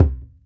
SingleHit_QAS10779.WAV